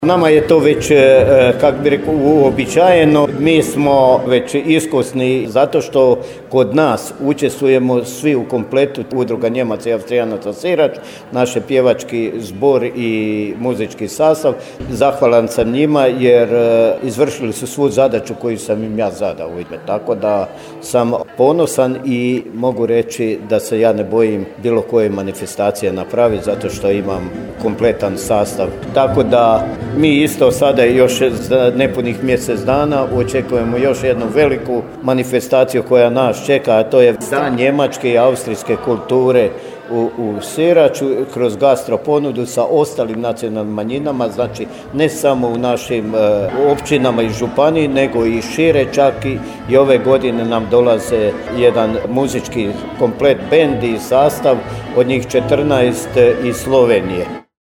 U subotu je upriličena 9. Smotra manjinskog stvaralaštva.